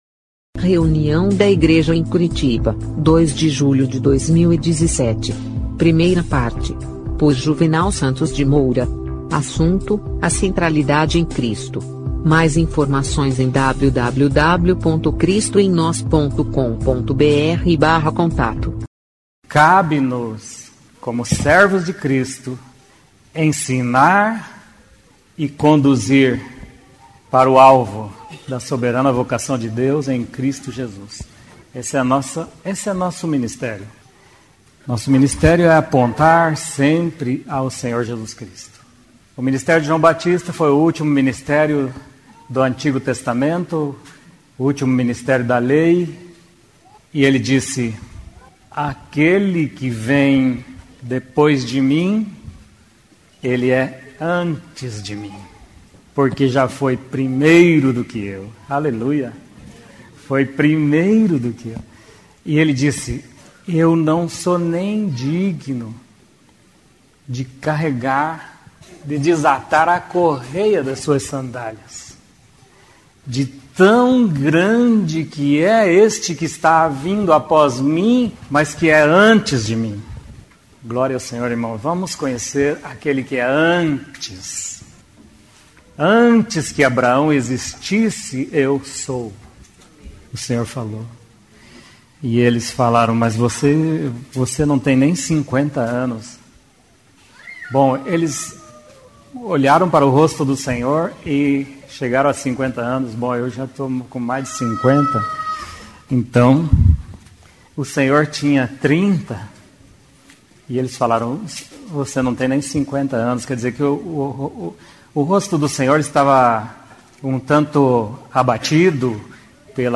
da reunião da igreja em Curitiba no dia 02/07/2017.